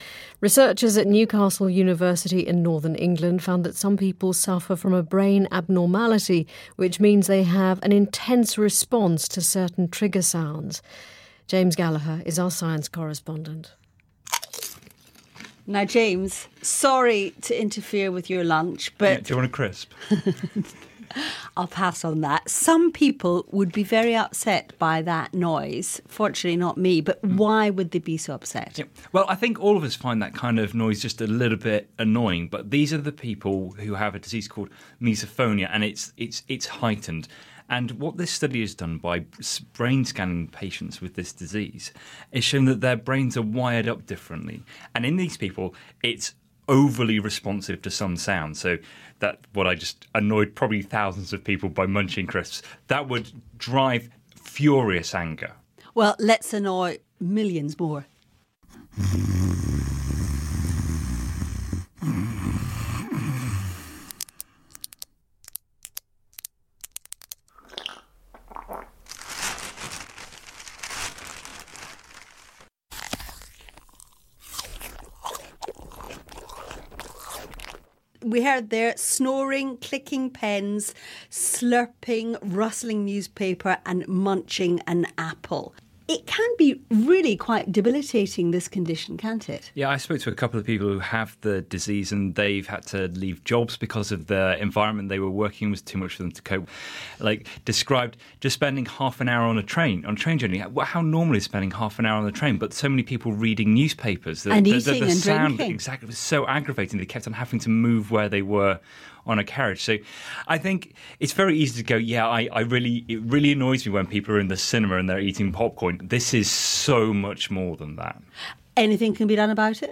Accent: British